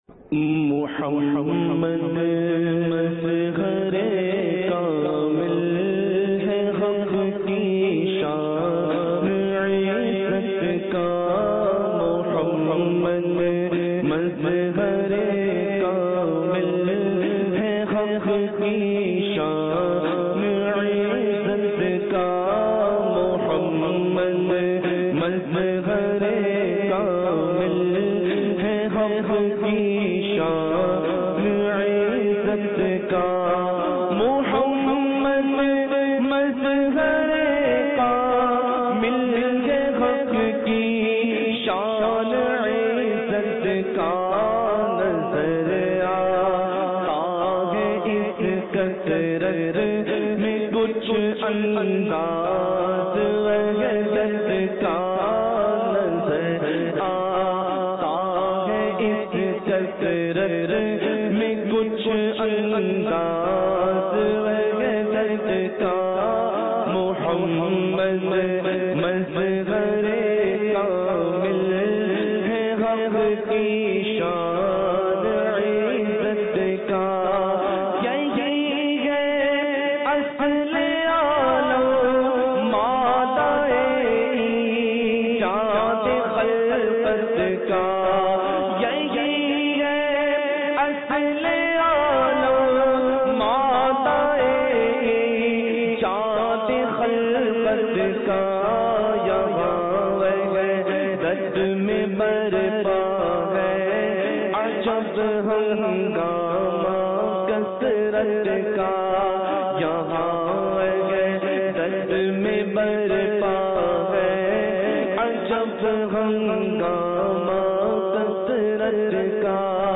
Muhammad Mazhar e Kamil Hai Feb 20, 2017 MP3 MP4 MP3 Share دعوتِ اسلامی کے تحت ہونےوالے اجتماعِ ذکرونعت میں پڑھا جانے والا سیدی اعلحٰضرت کا بہت ہی پیارا کلام خوبصورت آواز میں ضرور سنئے اور ایمان تازہ کیجئے۔